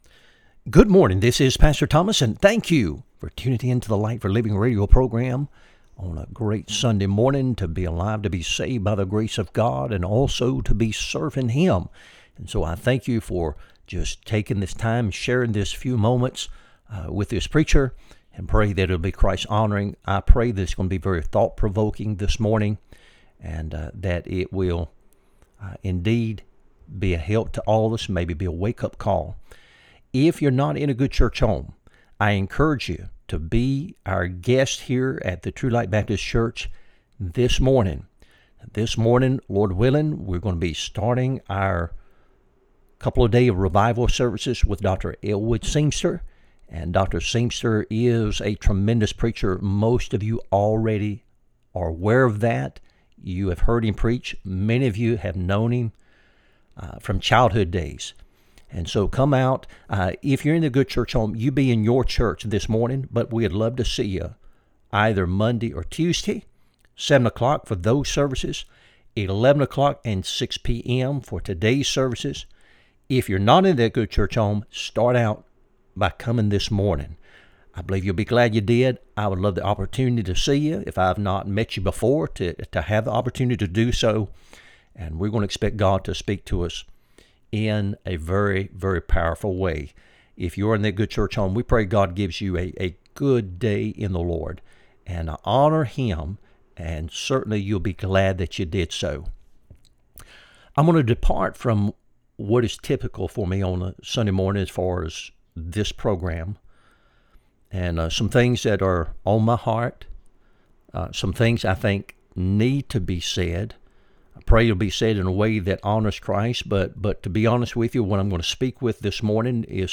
Light for Living Radio Broadcast